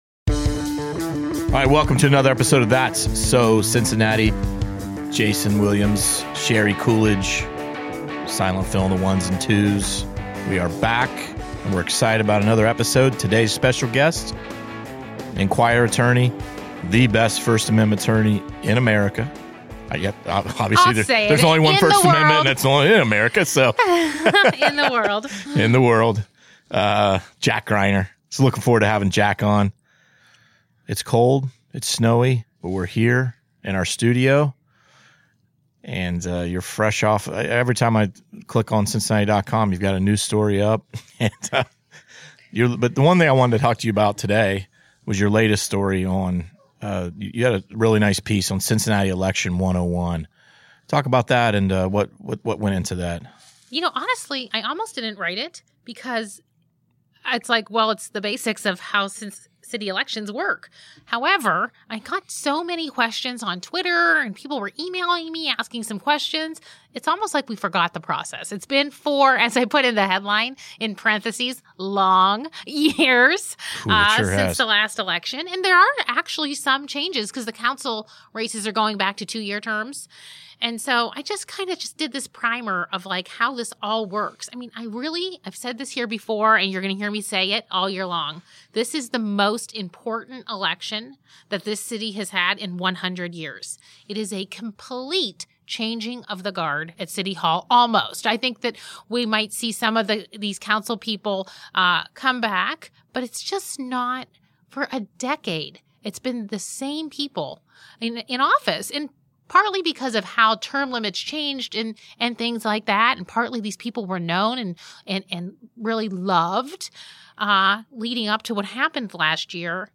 Ahead of the Legislature returning to session on Tuesday for the first time in six weeks, Ohio House Majority Leader Bill Seitz of Green Township joined The Enquirer's That's So Cincinnati podcast to talk about the role GOP lawmakers, DeWine and Acton are playing in reopening the economy.
The Seitz interview begins at the 30:15 mark in the episode.